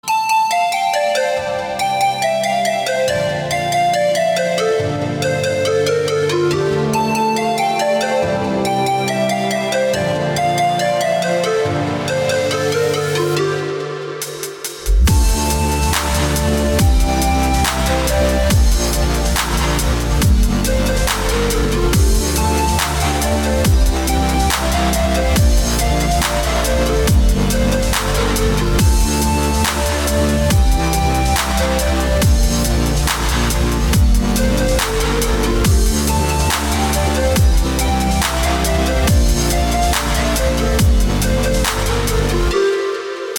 • Качество: 320, Stereo
красивые
спокойные
chillstep